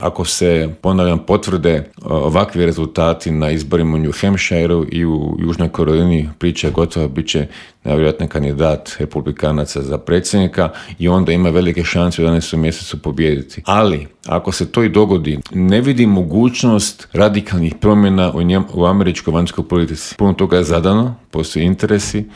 Bivšeg ministra vanjskih i europskih poslova Miru Kovača u Intervjuu Media servisa upitali smo - je li ga iznenadio postupak SAD-a?